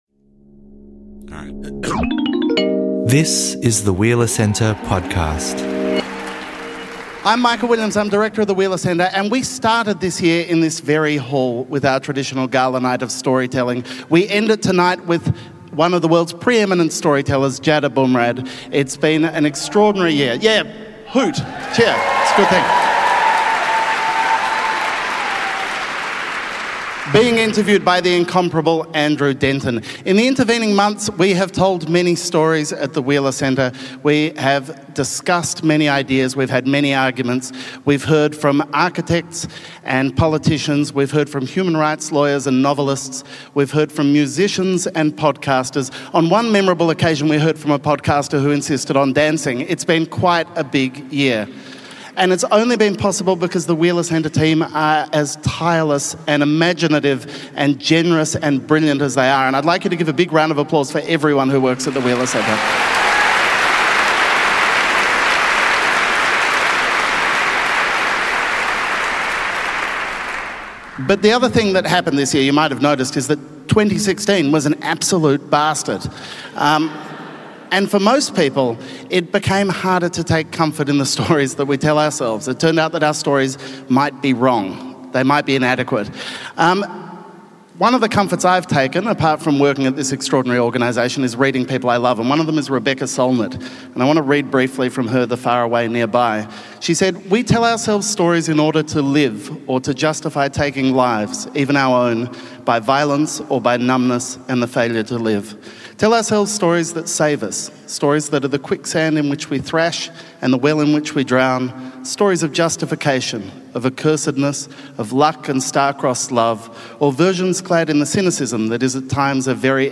In Melbourne for the first time, Abumrad chats with veteran broadcaster Andrew Denton.